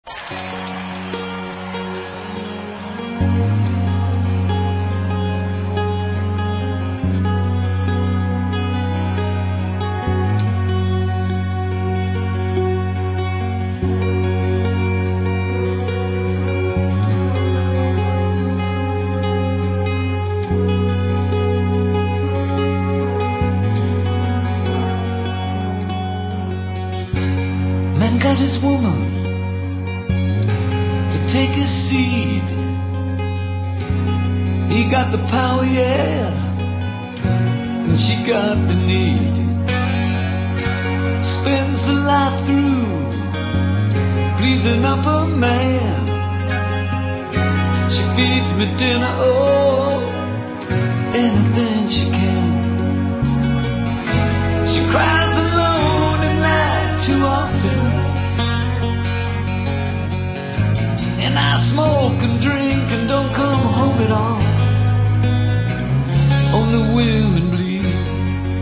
Leur qualité sonore a par ailleurs été réduite.